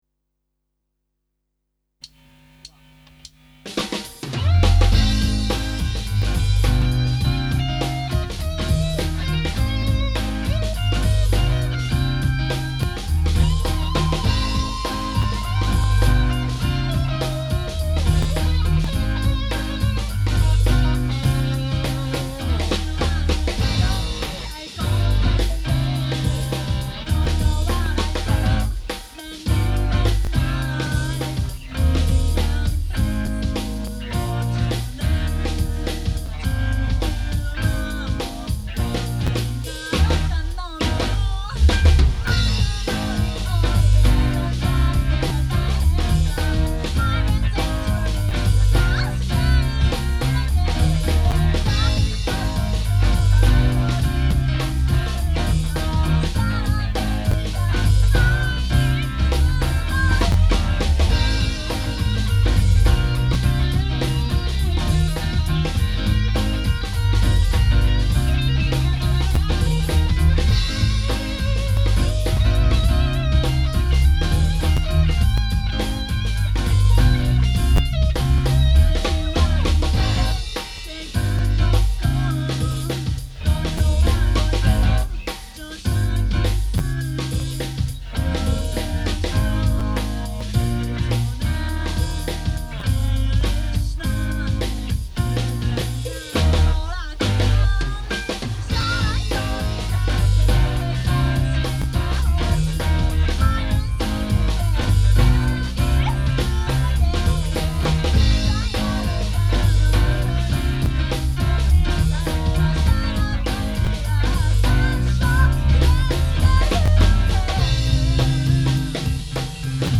スタジオ音源で自慢になりそうでならないものを載せていこうと思います。